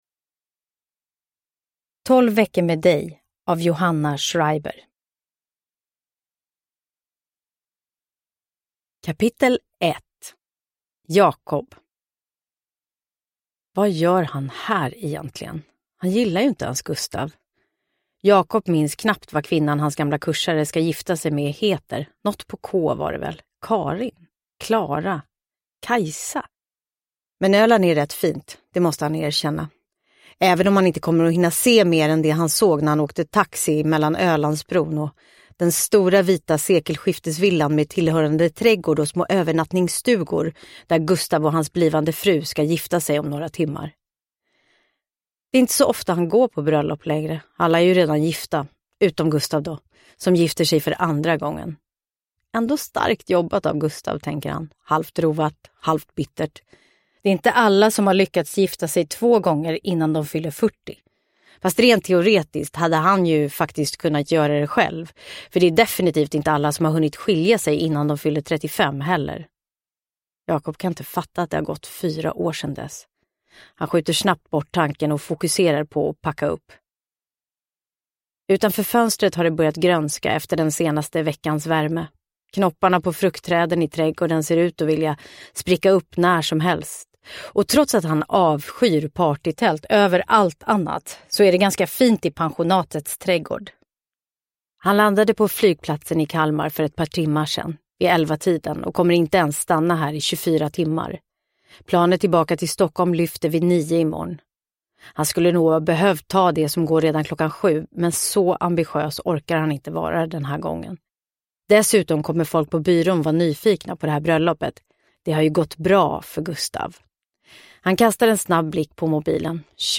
Tolv veckor med dig – Ljudbok – Laddas ner
Uppläsare: Helena af Sandeberg